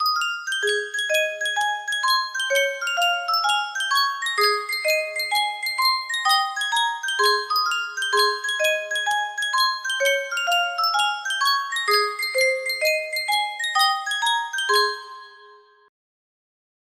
Yunsheng Music Box - Algeria National Anthem 5763 music box melody
Full range 60